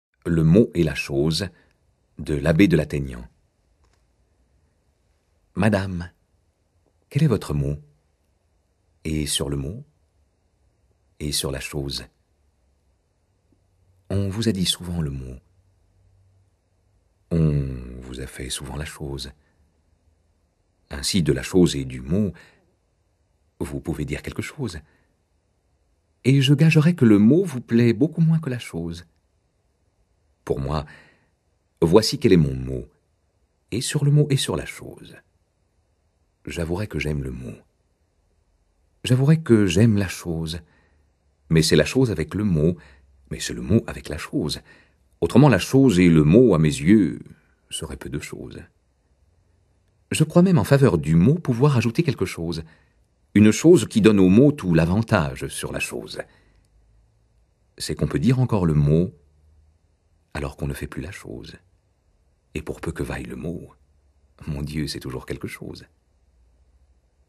Diffusion distribution ebook et livre audio - Catalogue livres numériques
de sa belle voix classique et chaude, les incarne avec une joie non dissimulée et ce qu?il faut de coquinerie retenue?